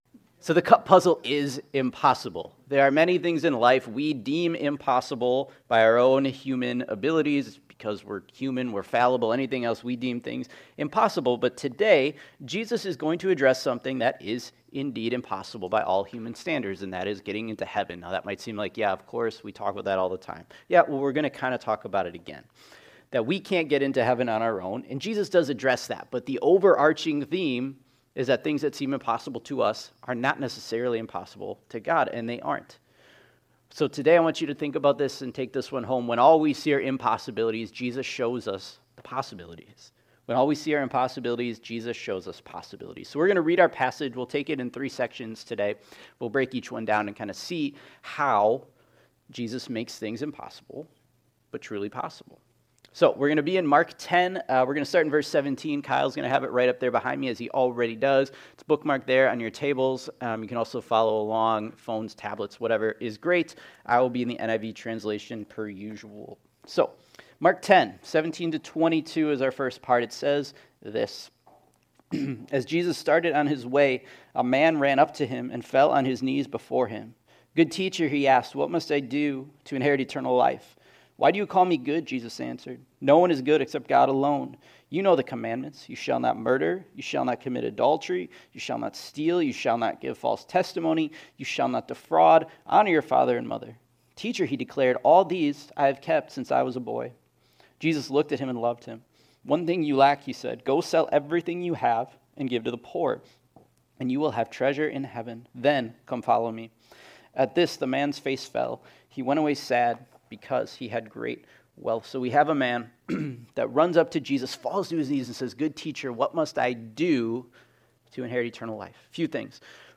Mark Community Impossible Obstacles Oh But God Possible Rebellion Risk Sacrifice Sunday Morning There are many things in life that may seem impossible.